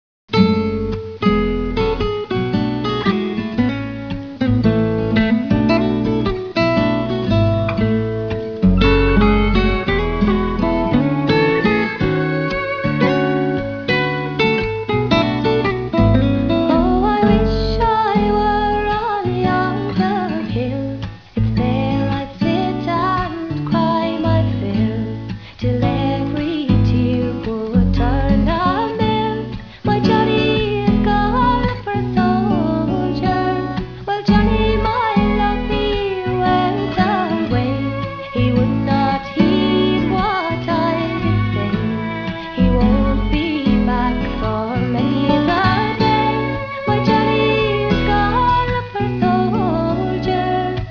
Contemporary/Traditional